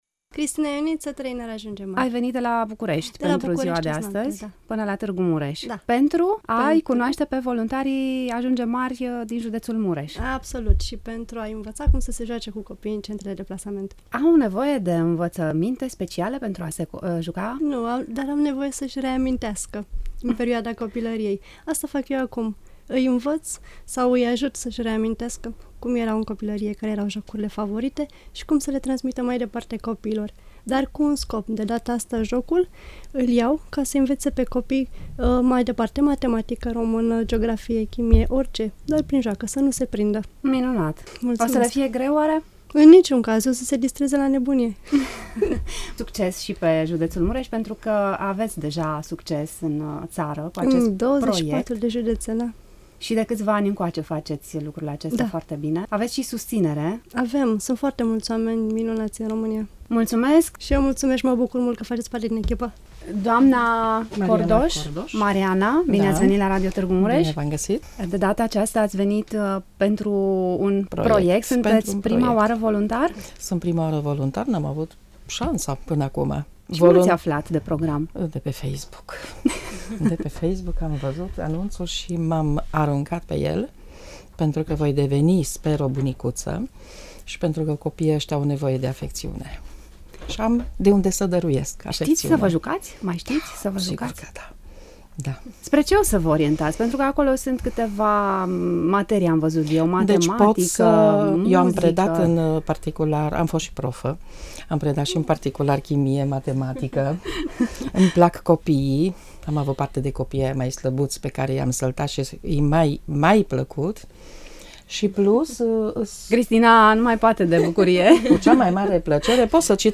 Studioul 3 de la Radio Tg.Mureș a găzduit prima întâlnire a voluntarilor din cadrul acestui program educațional, născut din dorința de a-i ajuta pe copiii din centrele de plasament să devină oameni mari, responsabili, independenți si încrezători în propriile forțe.